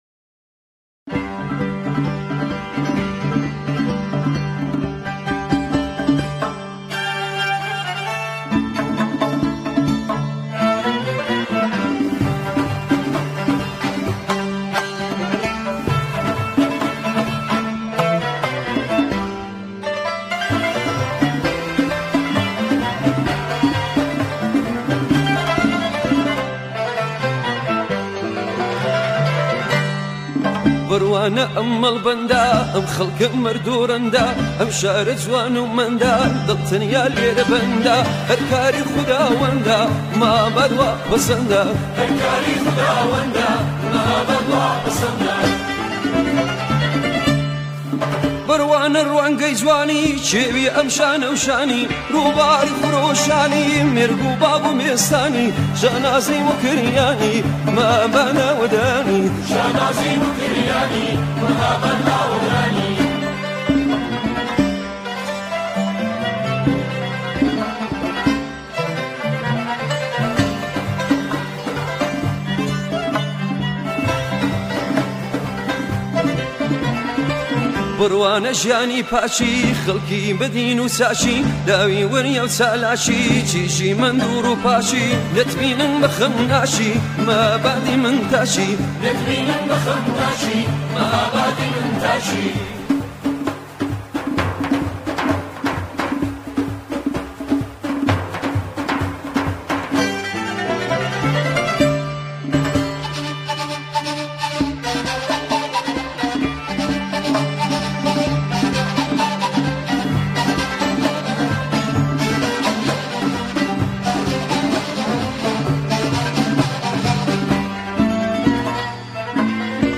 سرودهای شهرها و استانها